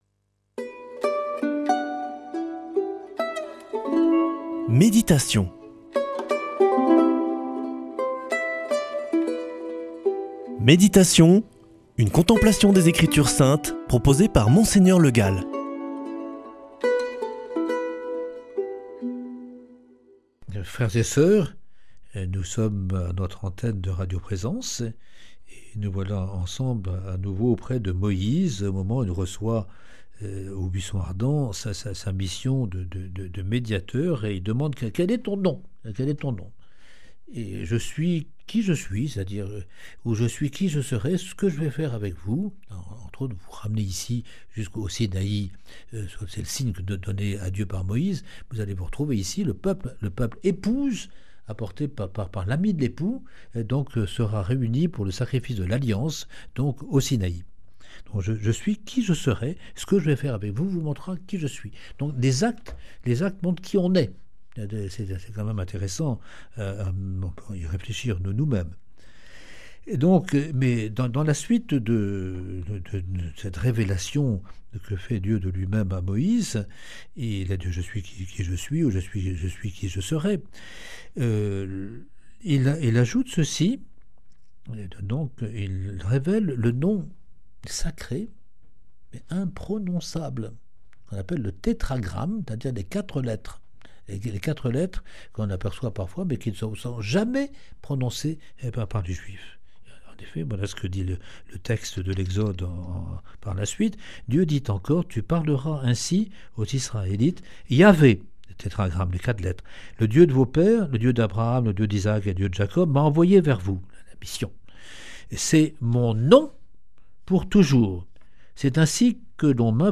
lundi 26 mai 2025 Méditation avec Monseigneur Le Gall Durée 7 min
Présentateur